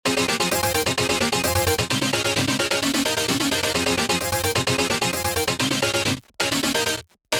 An absolute classic throwback to the early, early electro sound from the 2000s
Runner_-3-Runner_130-Stack_Chops_1.mp3